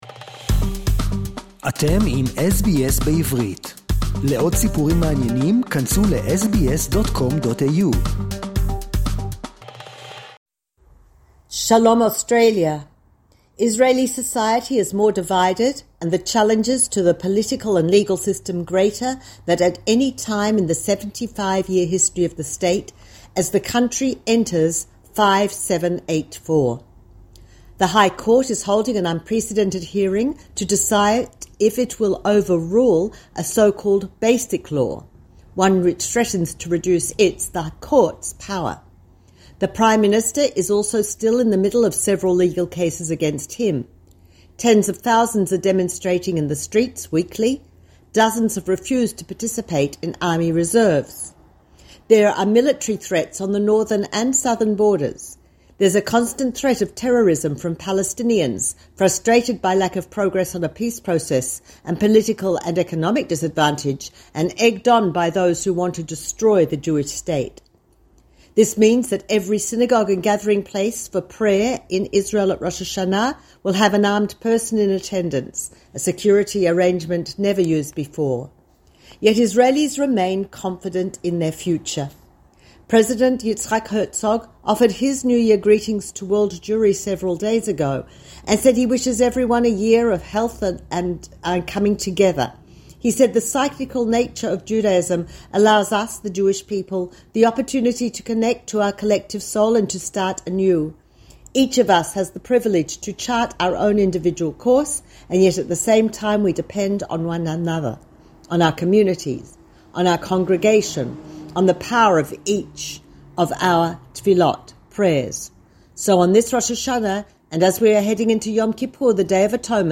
Jewish New Year Report From Jerusalem